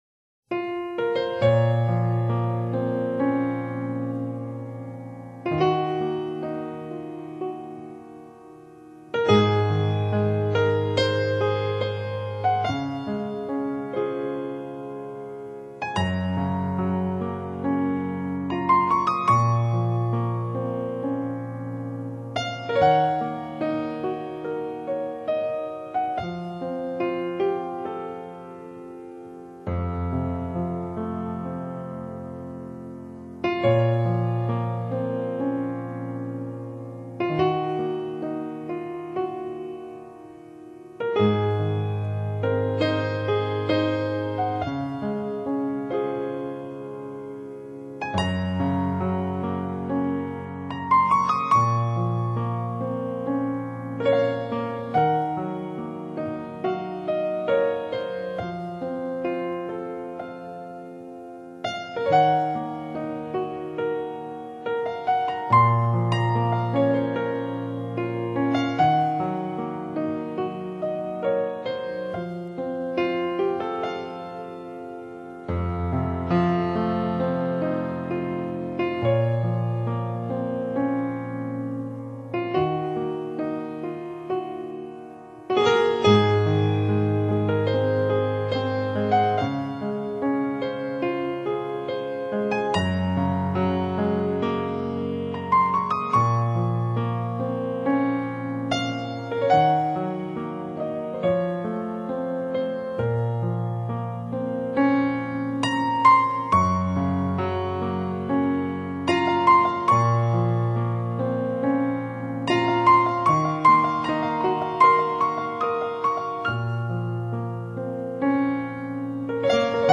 风格的钢琴独奏专辑,曲风缓慢恬静,带着丝丝淡